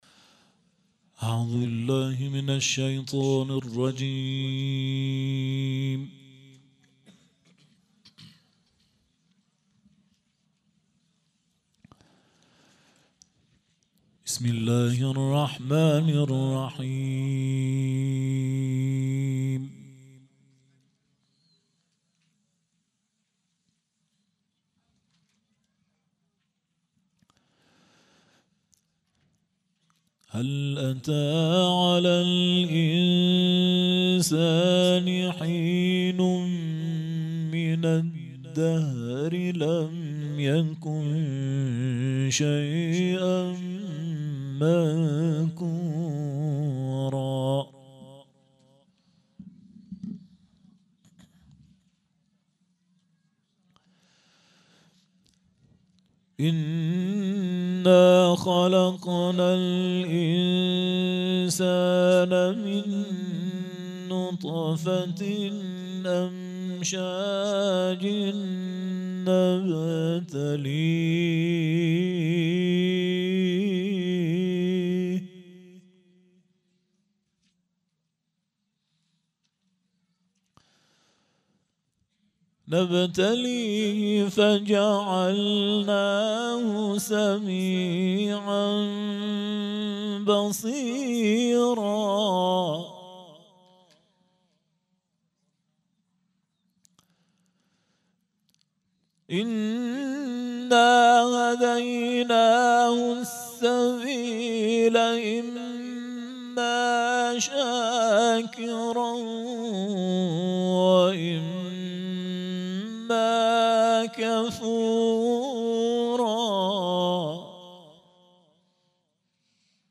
فاطمیه97- مجمع دلسوختگان بقیع- شب سوم- قرائت قرآن